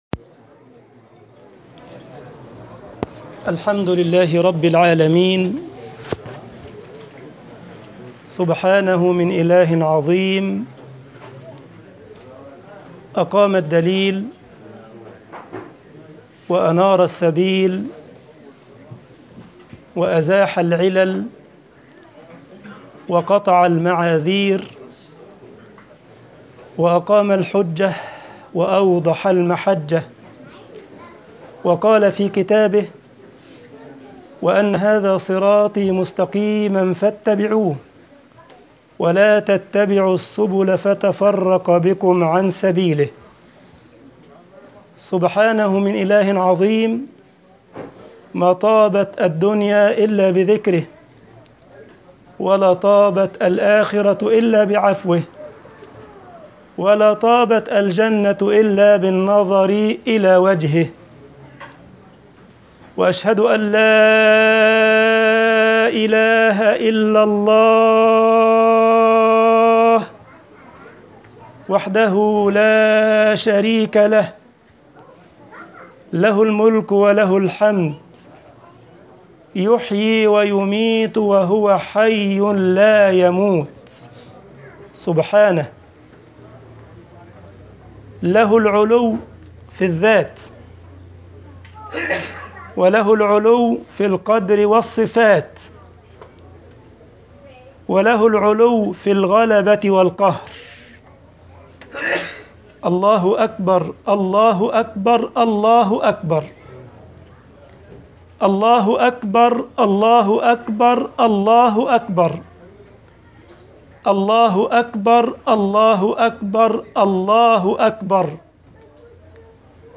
خطبة عيد الأضحي
Khutbatu eid aladhha.mp3